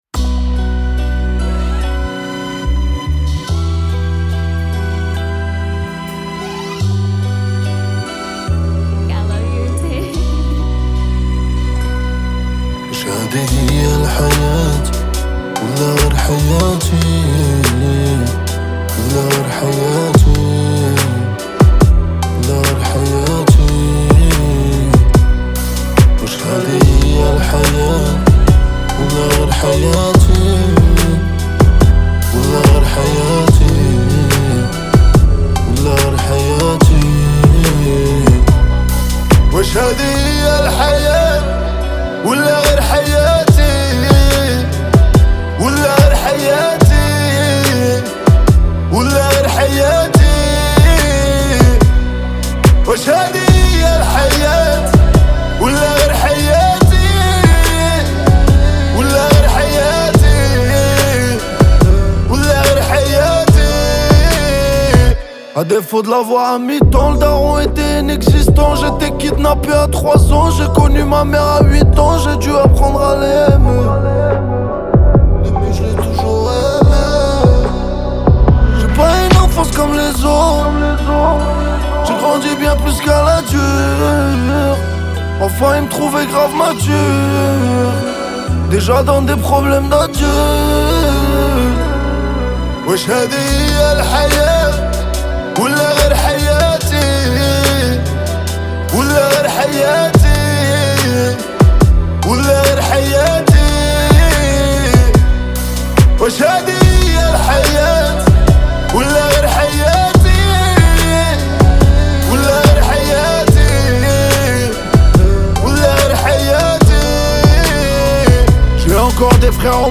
3/100 Genres : raï, moroccan chaabi Écouter sur Spotify